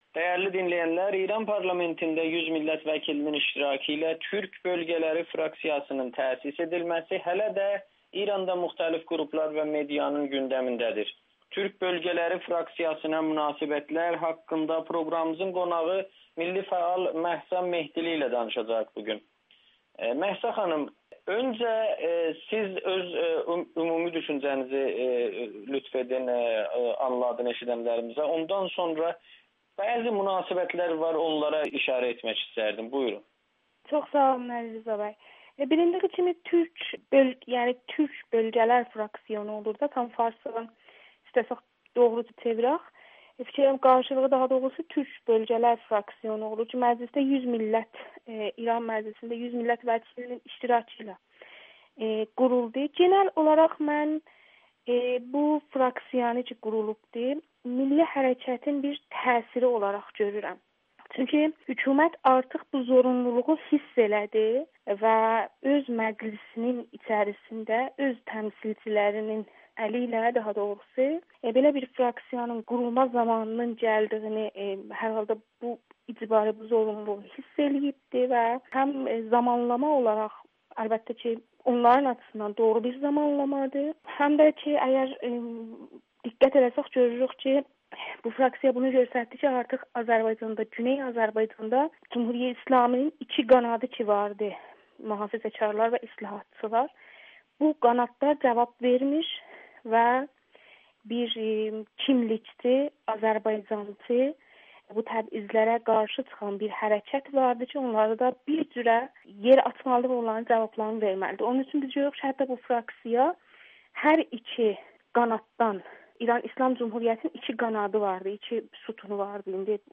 Türk Fraksiyasının təsisi ‘azəri kimliyi’ layihəsinin nəticə vermədiyini göstərdi [Audio-Müsahibə]